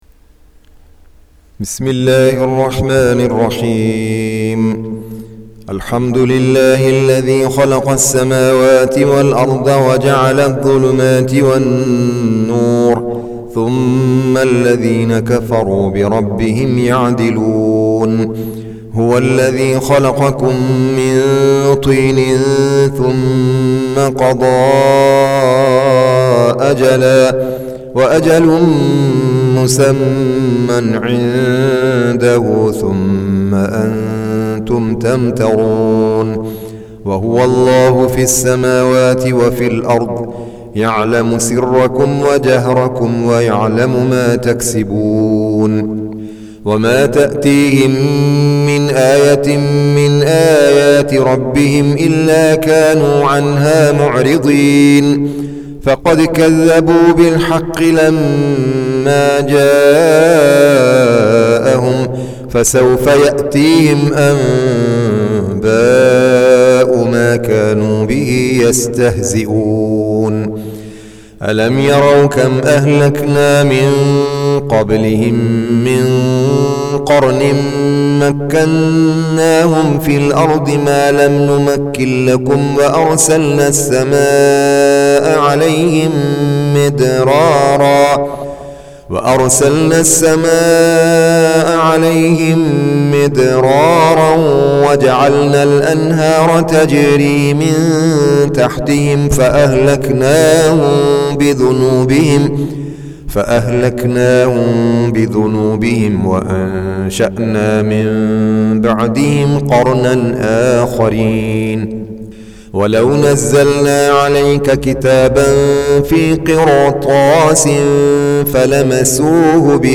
Surah Sequence تتابع السورة Download Surah حمّل السورة Reciting Murattalah Audio for 6. Surah Al-An'�m سورة الأنعام N.B *Surah Includes Al-Basmalah Reciters Sequents تتابع التلاوات Reciters Repeats تكرار التلاوات